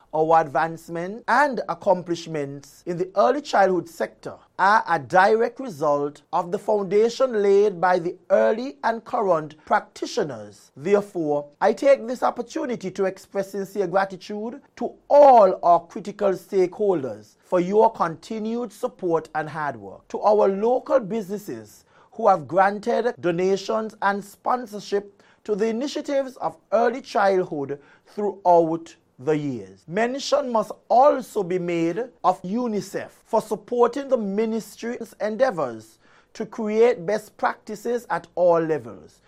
Addressing the nation at the beginning of the month was Federal Minister of Education, Hon. Dr. Geoffrey Hanley: